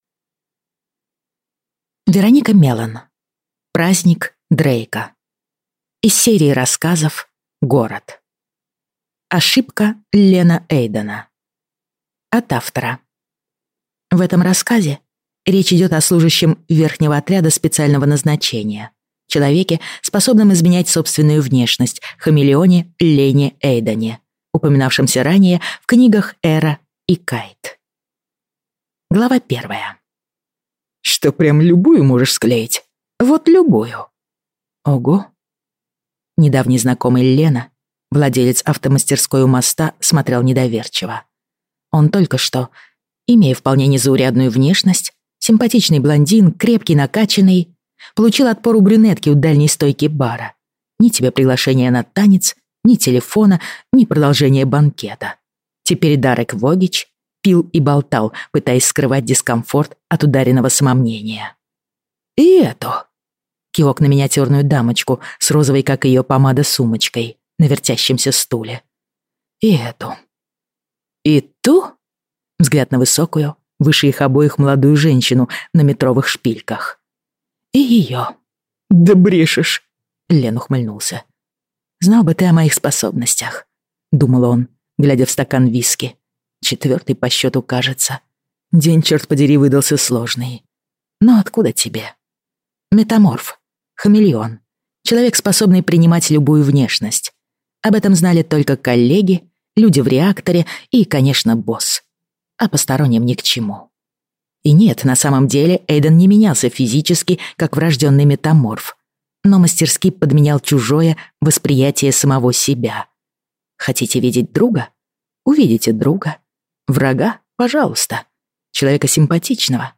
Аудиокнига Праздник Дрейка | Библиотека аудиокниг